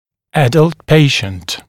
[‘ædʌlt ‘peɪʃnt] [ə’dʌlt][‘эдалт ‘пэйшнт] [э’далт]взрослый пациент